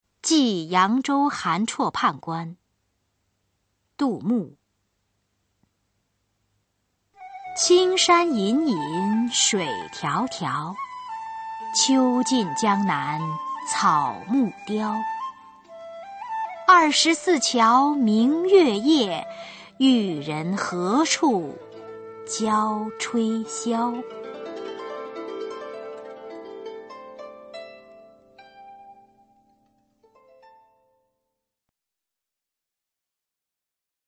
[隋唐诗词诵读]杜牧-寄扬州韩绰判官 古诗文诵读